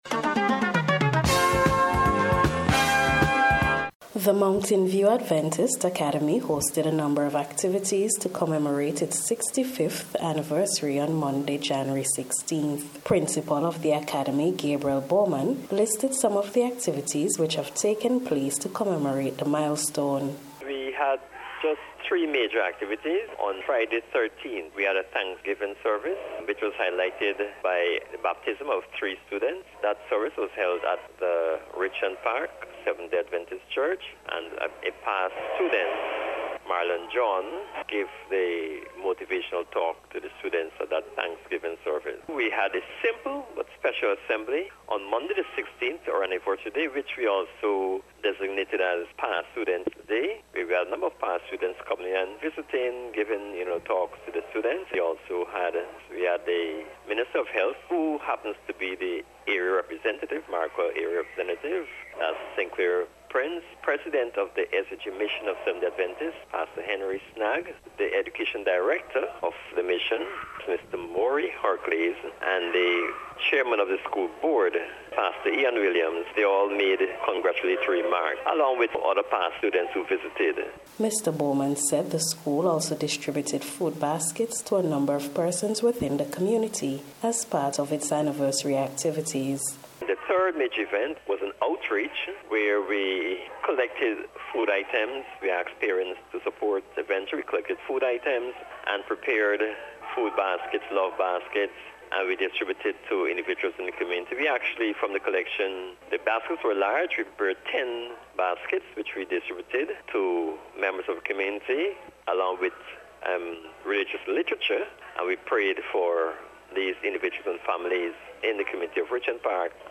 MOUNATAIN-VIEW-ACADEMY-REPORT.mp3